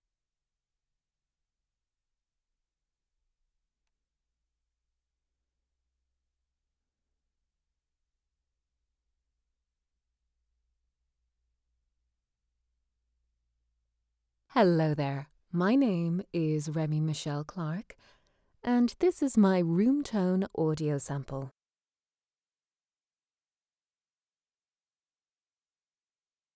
Female
Natural, Smooth, Warm
Neutral Irish (native), Dublin (native), RP, Standard British, General London, Californian, Standard US
Microphone: Rode NT2